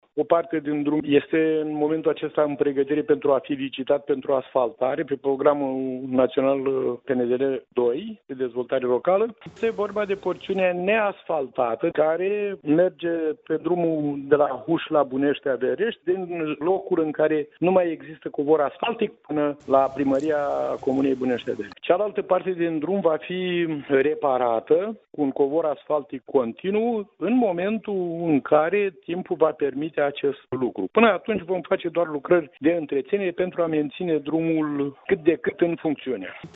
Preşedintele Consiliului Judeţean Vaslui, Dumitru Buzatu, a precizat, pentru postul nostru de radio, că o parte din Drumul Judeţean 244D face obiectul unei licitaţii în cadrul Programului Naţional de Dezvoltare Locală, urmînd să fie asfaltat, iar cealaltă porţiune va fi reparată odată ce vremea va permite acest lucru.